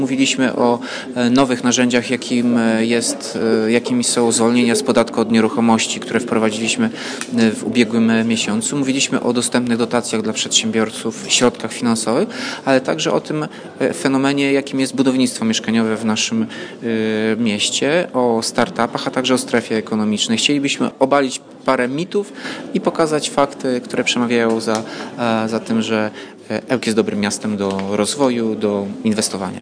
Spotkanie przedstawicieli biznesu i samorządowców odbyło się w Parku Naukowo-Technologicznym w Ełku.
– Jedną z nich jest niewątpliwie zwolnienie z podatku od nieruchomości – mówi Tomasz Andrukiewicz, prezydent Ełku.